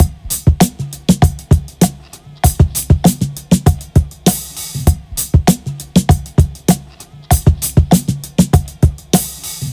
• 99 Bpm Drum Loop Sample E Key.wav
Free drum beat - kick tuned to the E note. Loudest frequency: 1537Hz
99-bpm-drum-loop-sample-e-key-qfk.wav